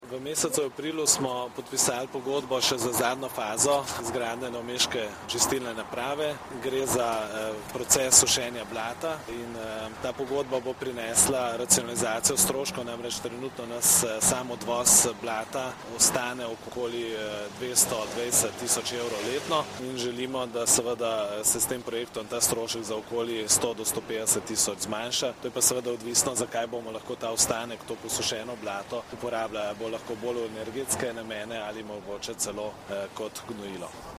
Novo mesto, 8. 5. 2015 – Danes dopoldne je župan Mestne občine Novo mesto Gregor Macedoni novinarje seznanil z novostmi pri aktualnih projektih Mestne občine Novo mesto.
Župan Gregor Macedoni o podpisu pogodbe o sušenju blata v Centralni čistilni napravi v Ločni